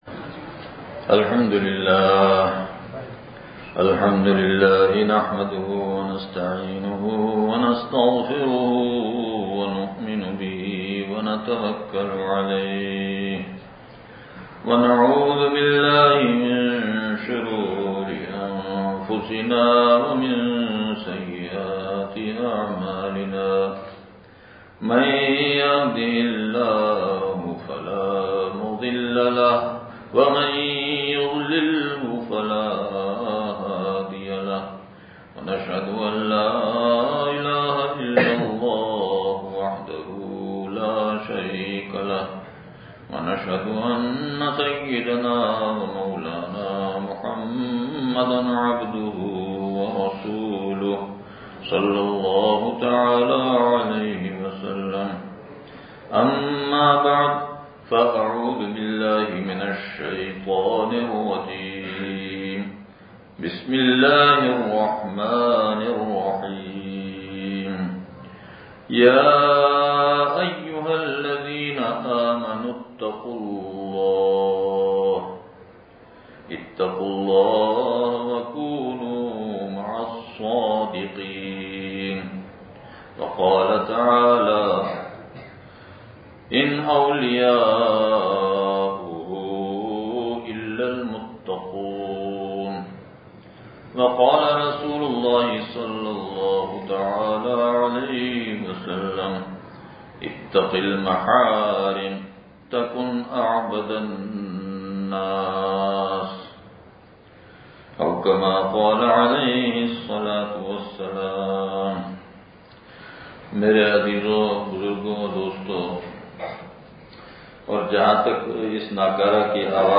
جامع مسجد سوسائٹی نواب شاہ سندھ (جمعہ بیان)